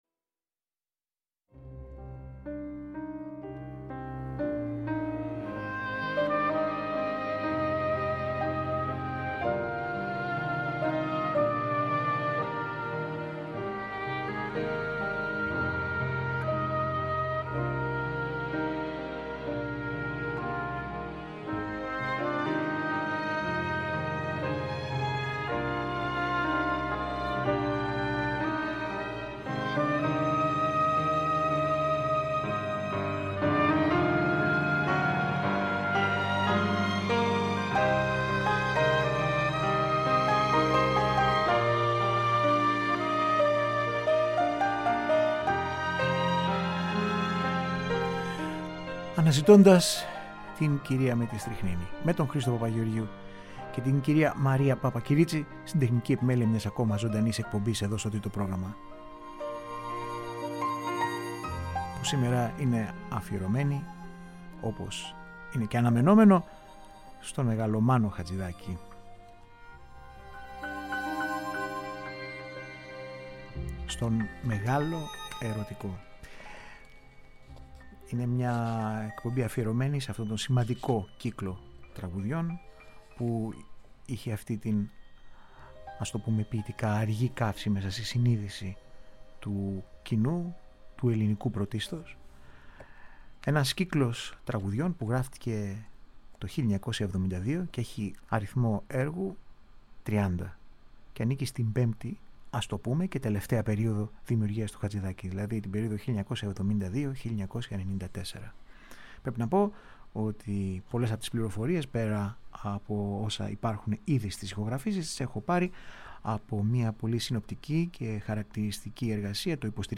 Ένα αφιέρωμα στο οποίο συμπεριλαμβάνεται σπάνιο ηχητικό ντοκουμεντο με τον Μάνο Χατζιδάκι να μιλά για το Εμβληματικό του έργο .